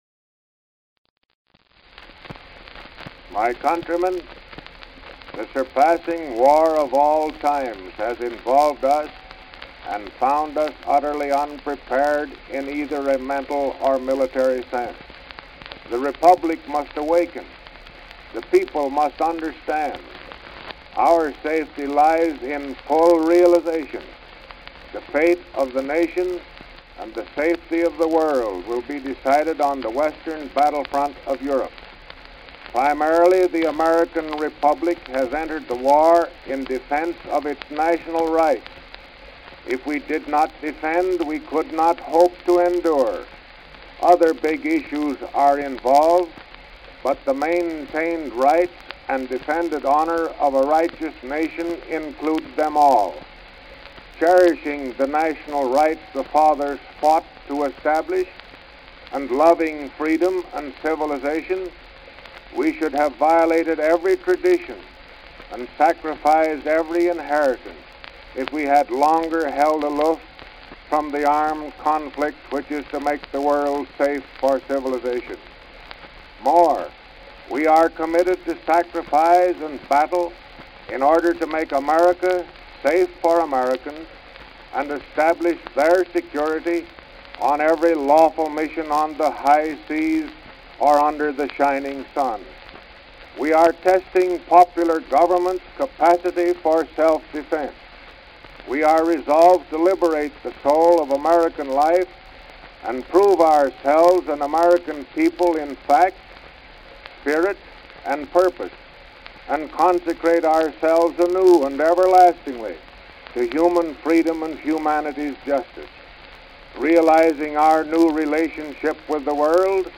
Presidential Speeches